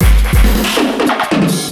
E Kit 10.wav